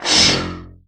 SCHOE2M.wav